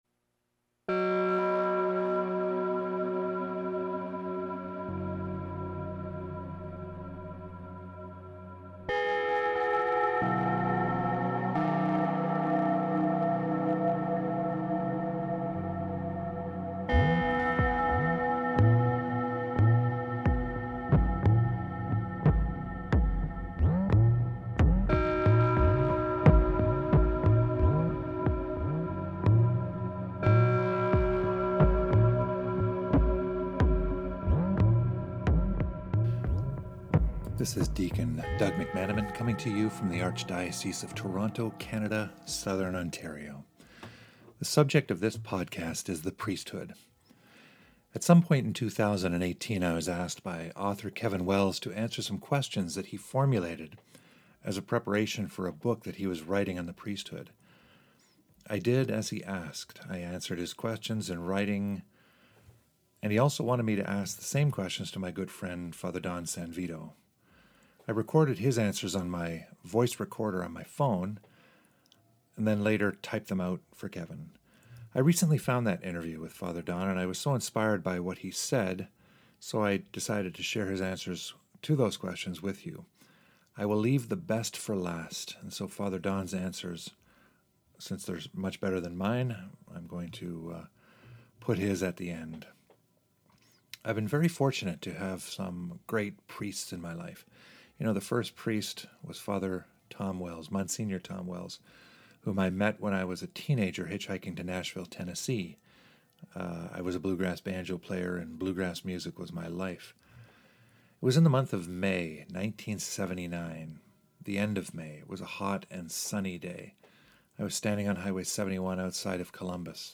priesthoodinterview.mp3